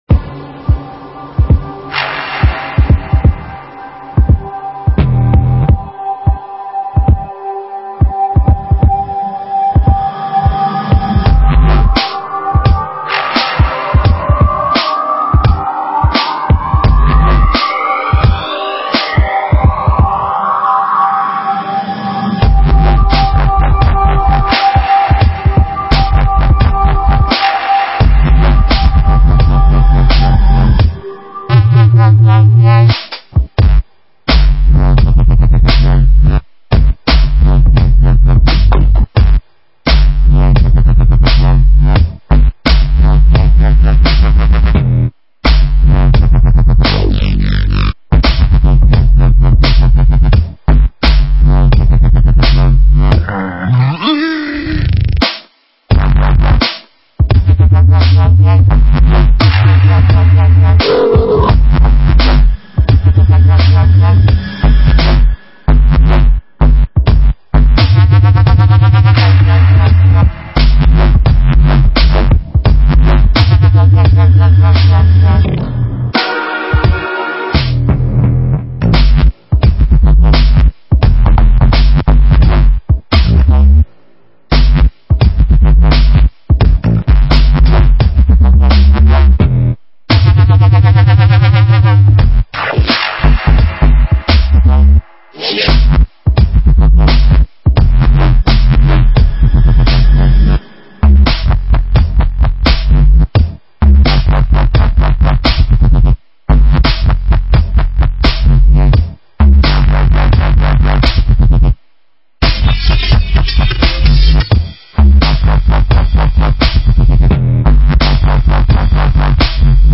Style: Dubstep, Drum & Bass